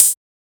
edm-hihat-38.wav